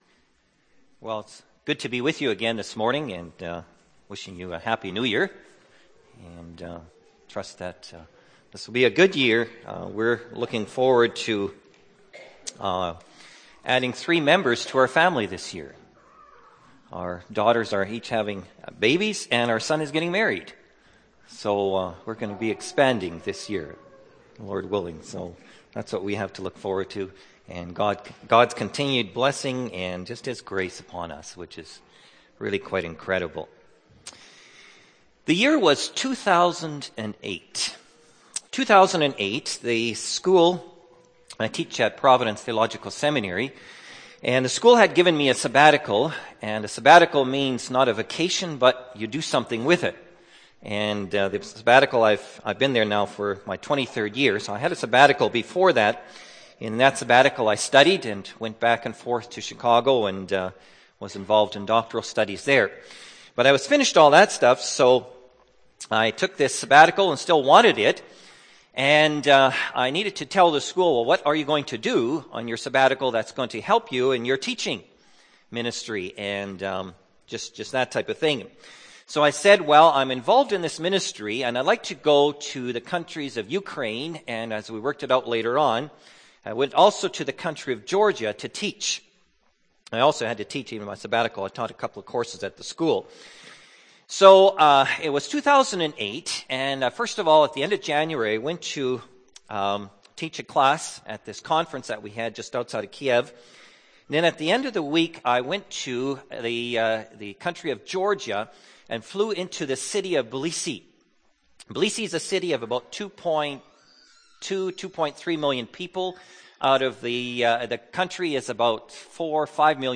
Jan. 1, 2012 – Sermon